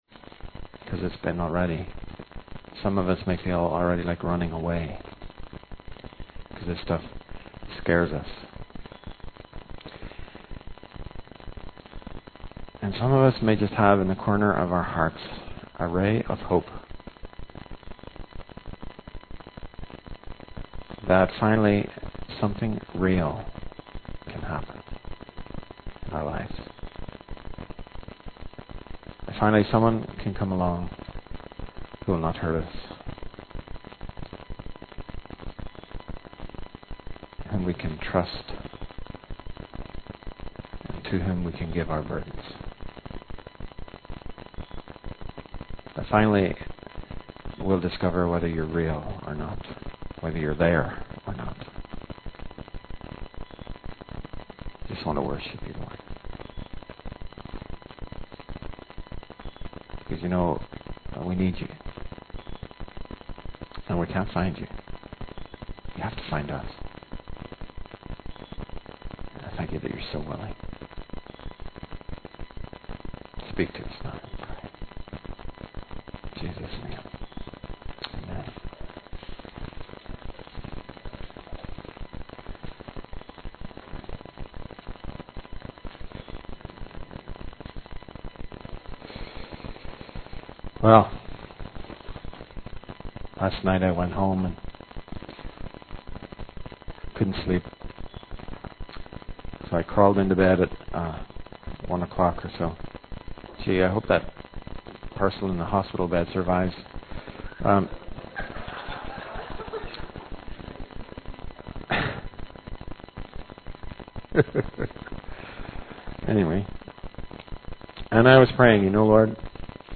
In this sermon, the speaker shares a personal story about taking his children to the swimming pool and how they would trust him to catch them when they jumped into the deep end. He then relates this to the story of Zacchaeus in the Bible, who let go of control and gave his whole life to Jesus. The speaker emphasizes that just like Zacchaeus, we need to let go of control and trust that God will catch us.